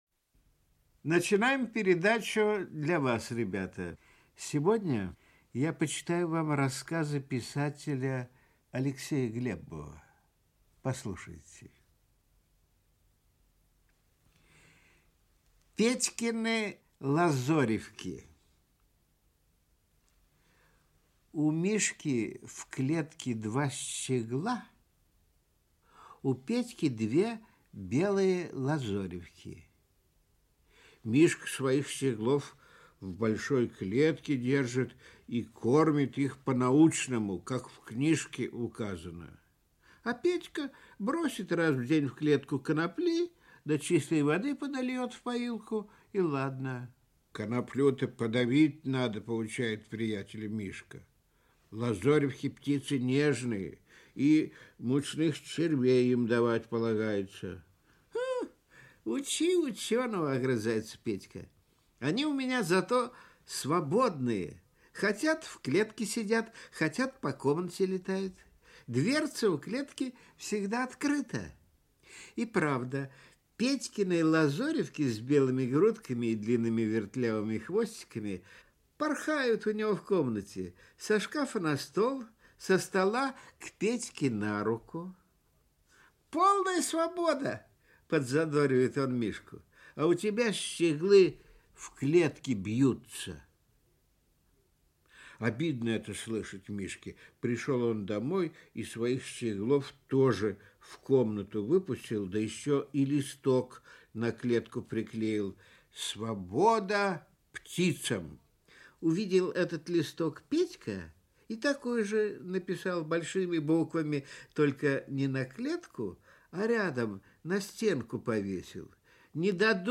Аудиокнига Рассказы | Библиотека аудиокниг
Aудиокнига Рассказы Автор Алексей Глебов Читает аудиокнигу Николай Литвинов.